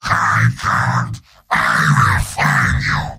Giant Robot lines from MvM.
{{AudioTF2}} Category:Heavy Robot audio responses You cannot overwrite this file.